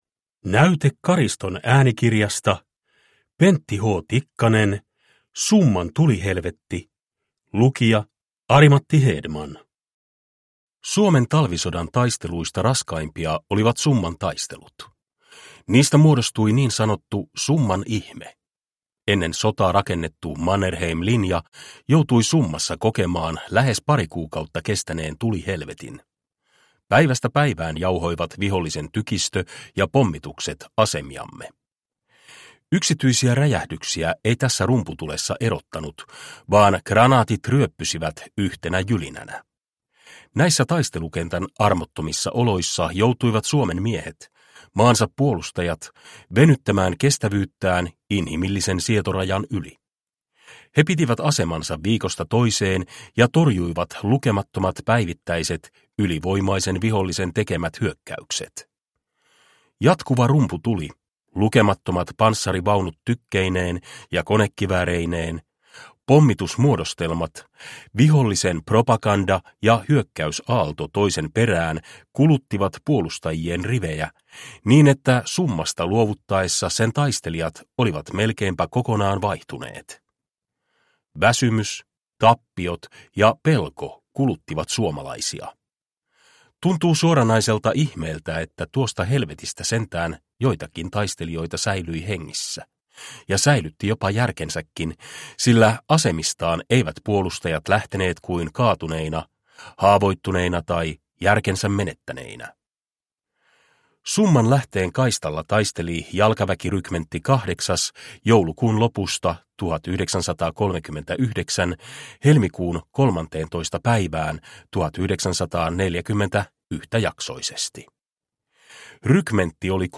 Summan tulihelvetti – Ljudbok – Laddas ner